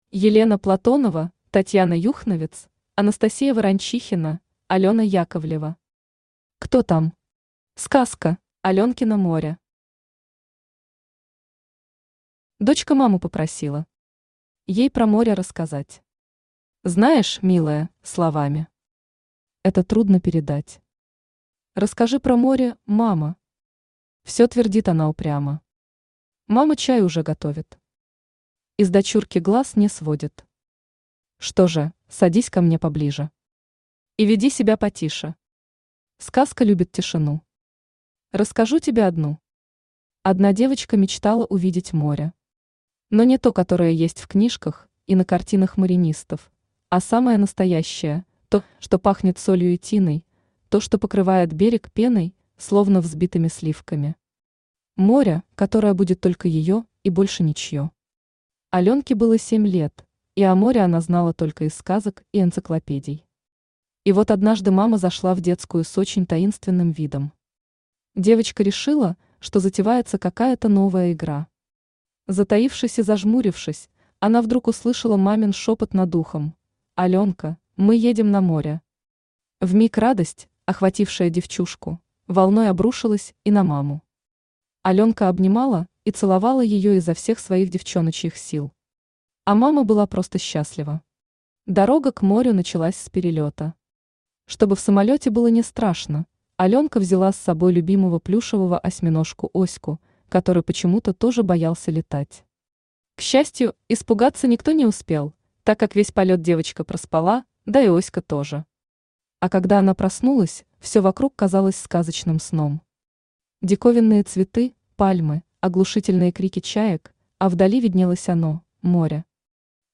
Аудиокнига Кто там? Сказка!
Автор Елена Платонова Читает аудиокнигу Авточтец ЛитРес.